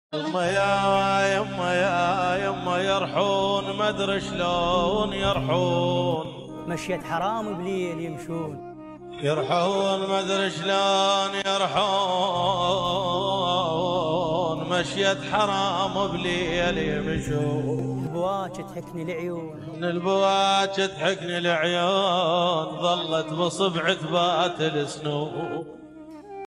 صوت وشعر